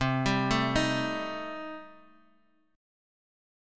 Cm6 Chord